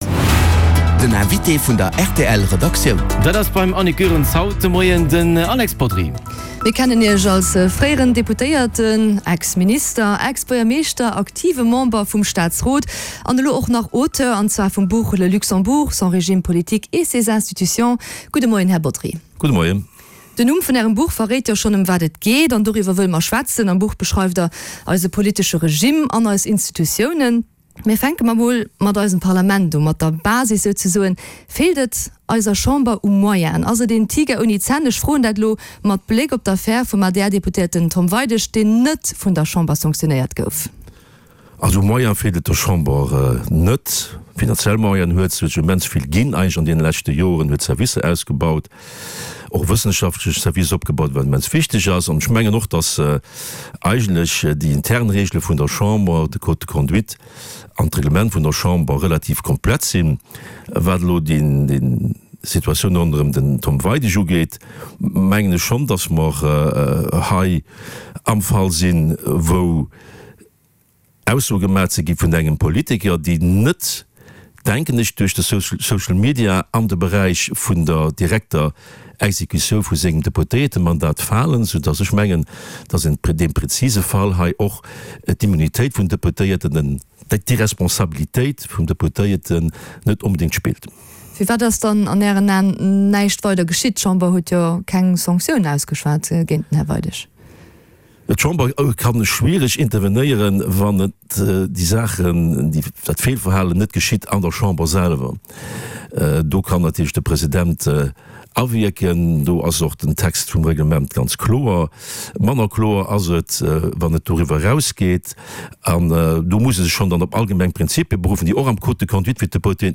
Den Hannergrondinterview mat Vertrieder aus Politik an Zivilgesellschaft